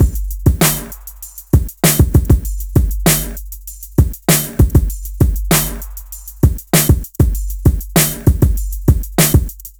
Rock Star - Beat 01.wav